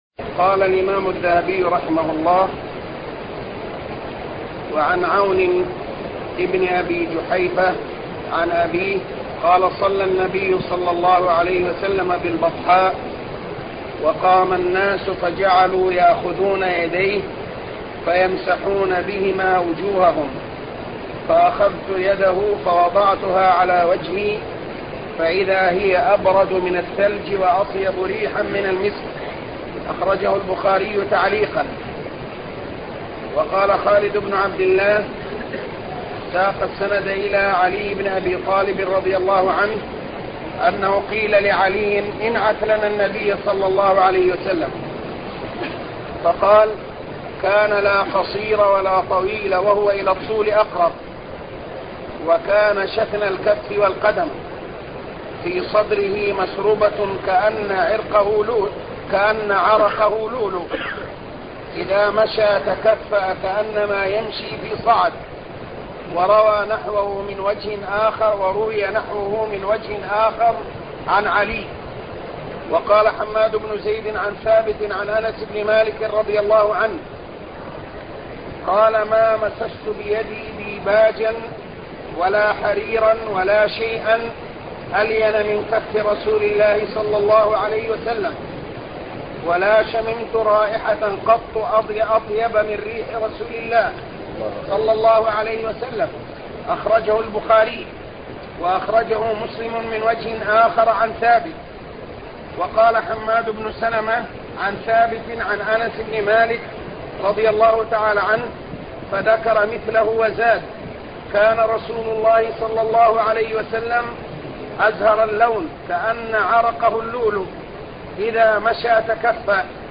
شرح السيرة النبوية الدرس 44